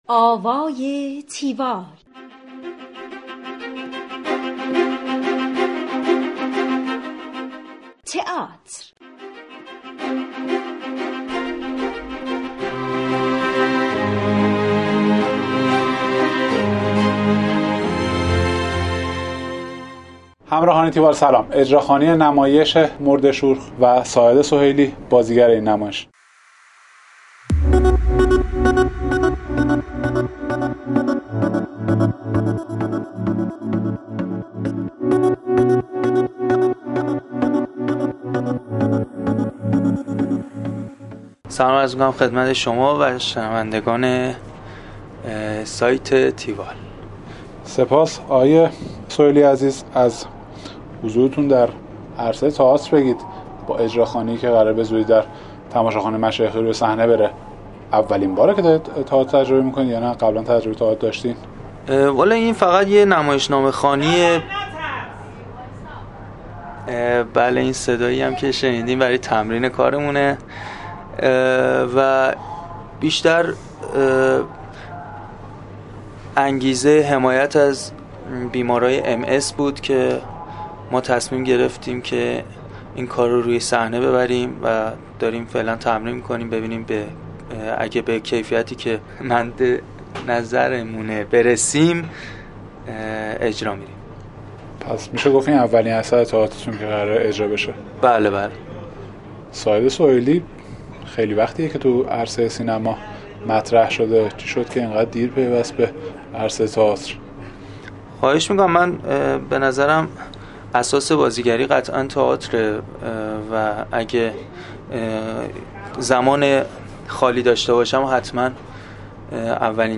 گفتگوی تیوال با ساعد سهیلی